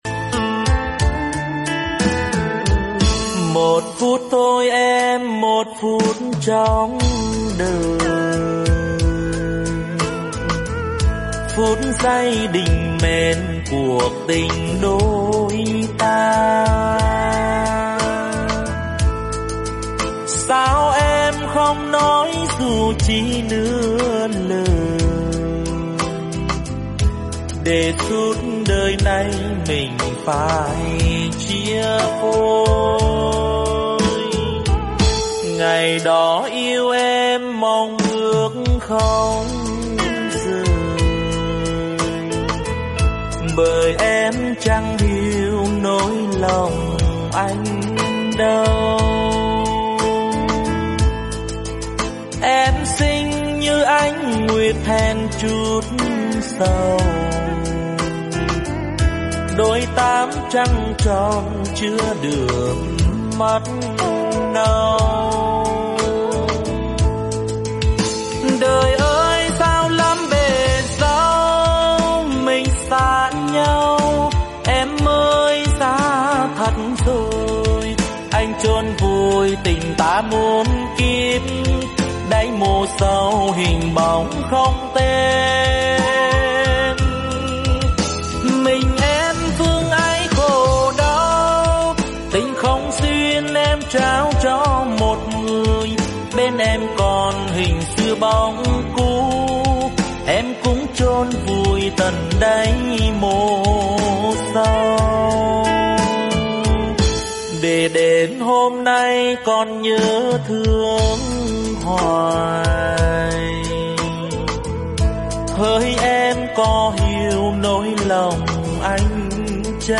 Nhạc Tình Của Lính. Nhạc Tình bolero. Nhạc Tâm Trạng.